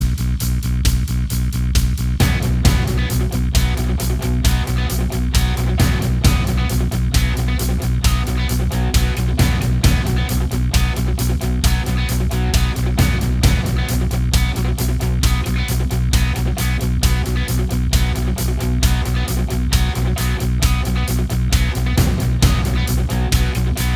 Minus Lead Guitars Rock 4:55 Buy £1.50